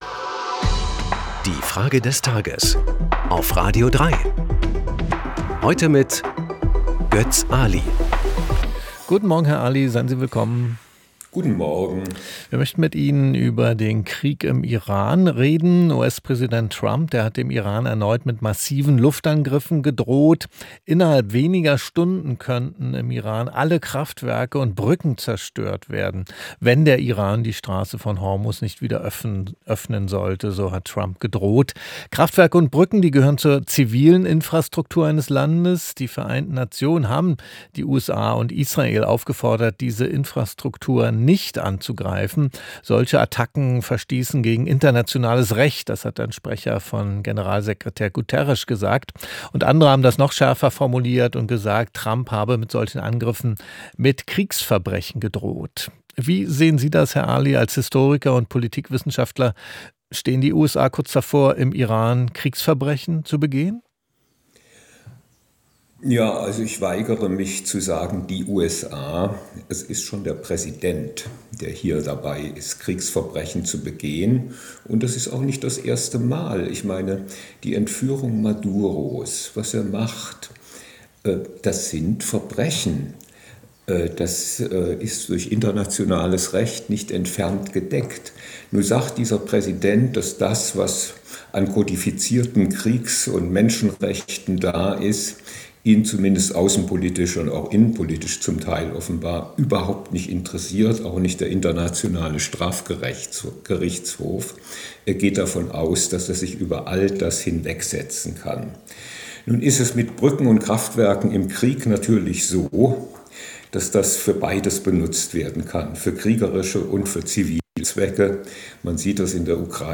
Historiker und Politikwissenschaftler Götz Aly.